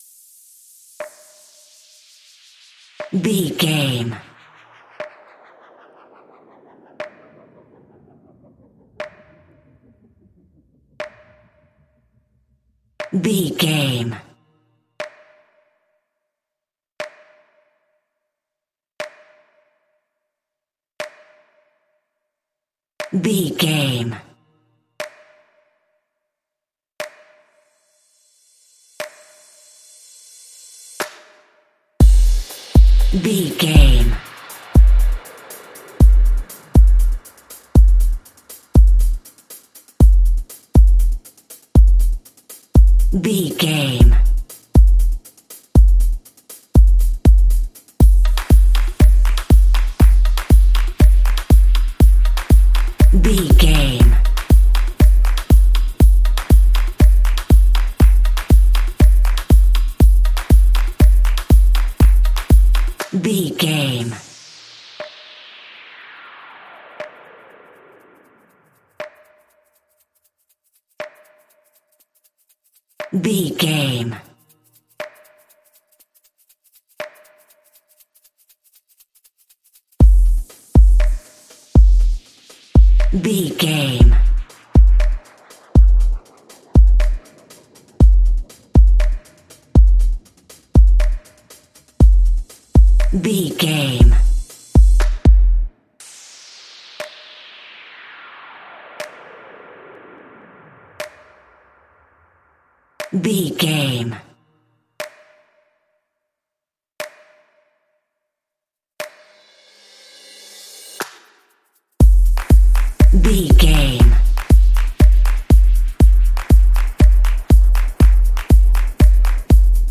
Uplifting
Ionian/Major
Fast
upbeat
electronic
energetic
drum machine